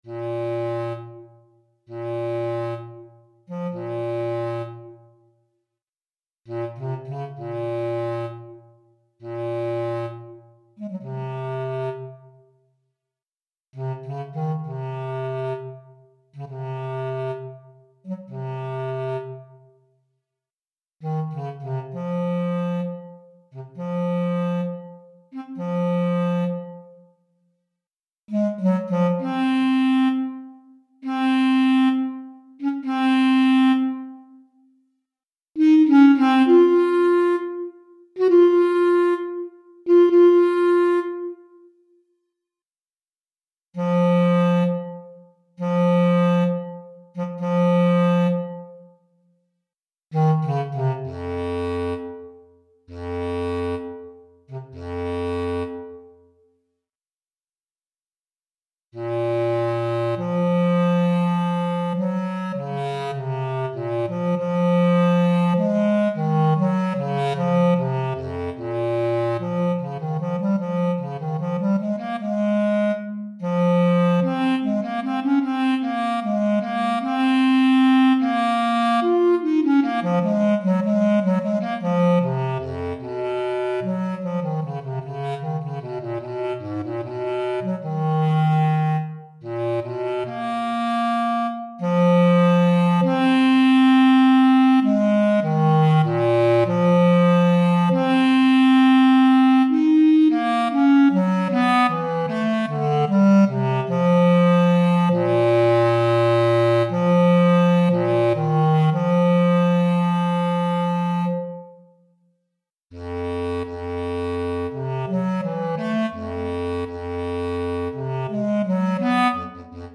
Pour clarinette basse solo DEGRE CYCLE 2